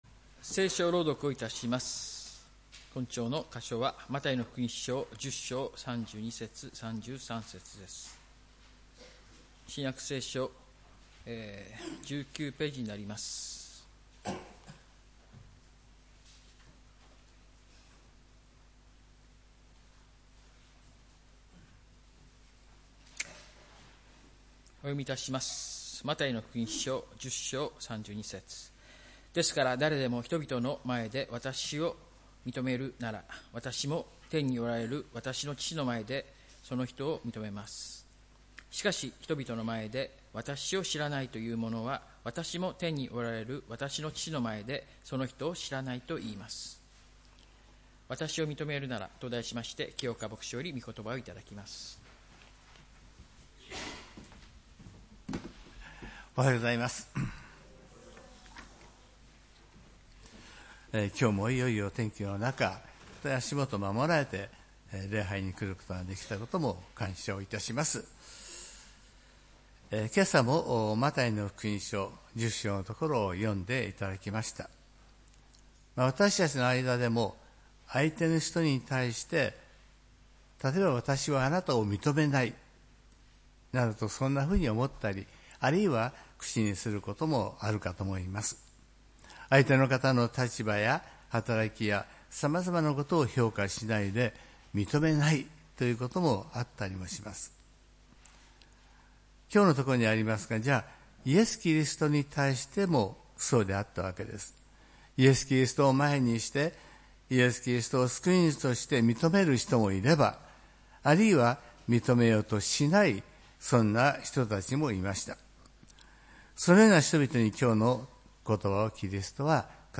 礼拝メッセージ「わたしを認めるなら」（４月26日）